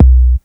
pcp_kick13.wav